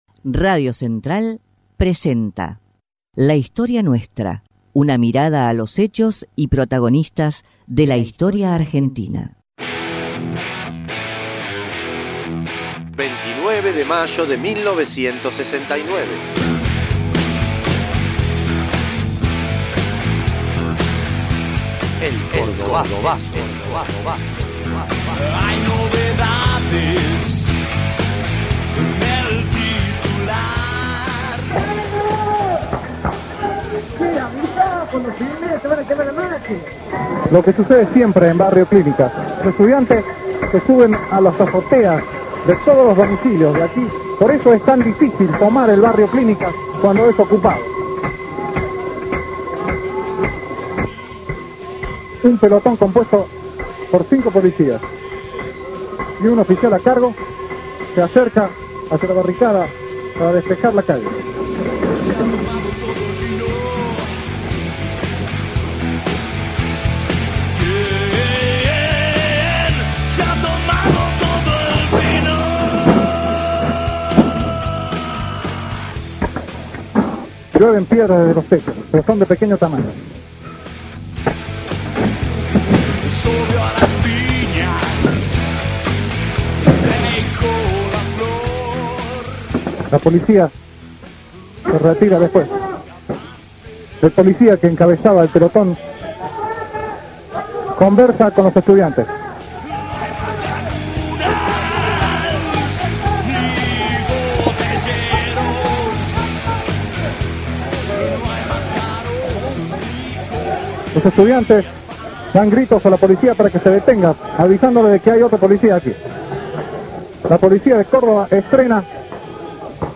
EL CORDOBAZO - informe especial - RADIO CENTRAL